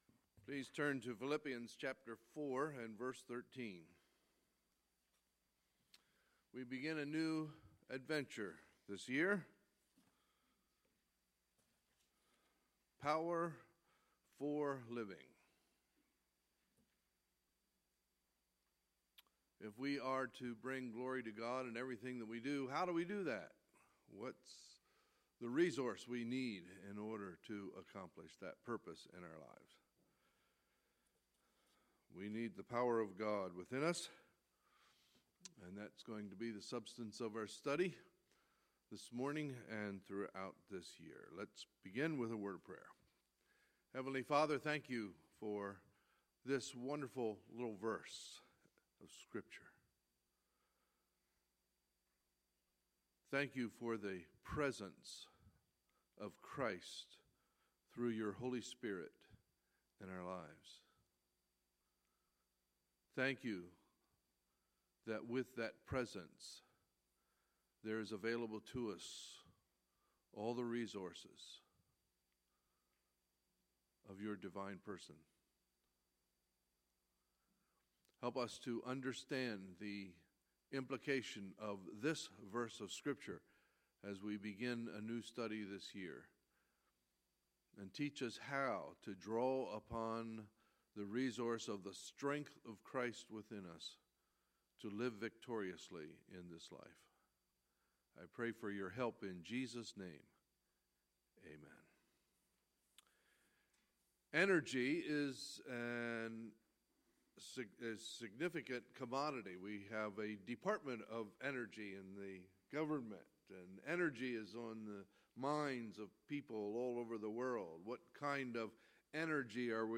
Sunday, January 1, 2017 – Sunday Morning Service